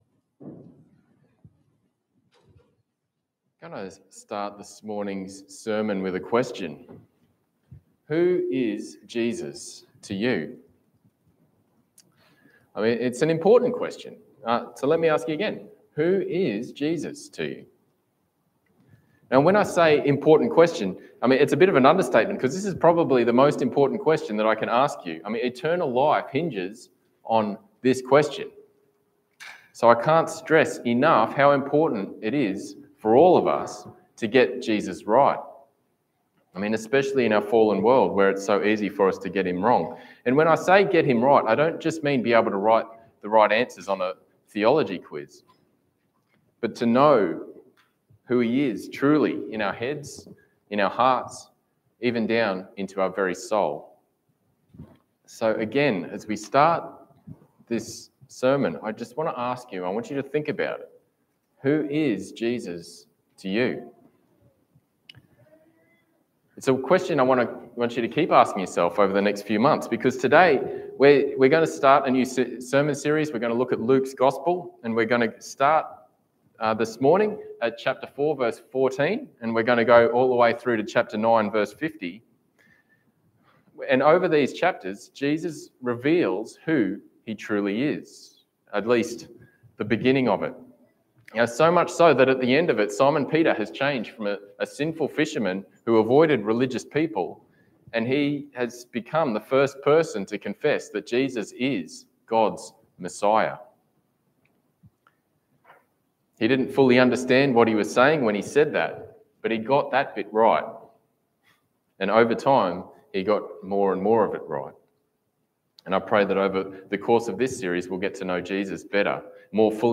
Passage: Luke 4:14-30 Service Type: Sunday Morning